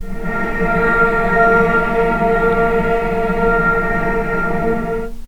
vc_sp-A#3-pp.AIF